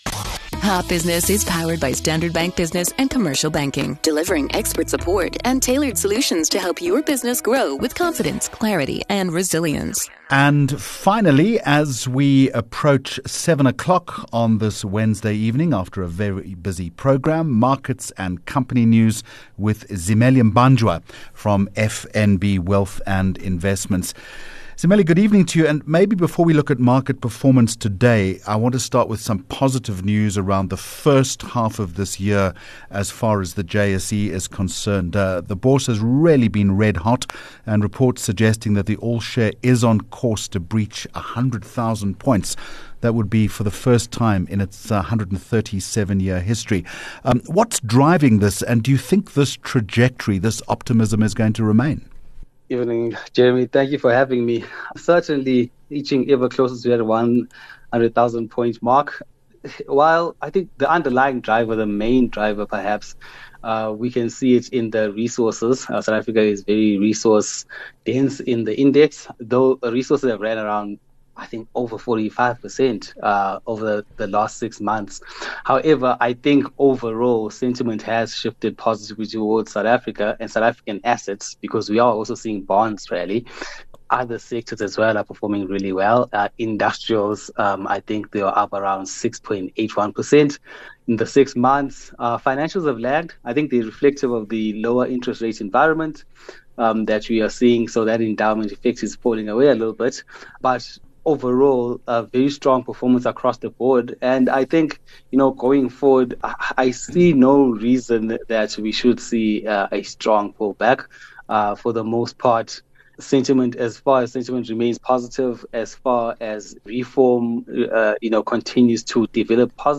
2 Jul Hot Business Interview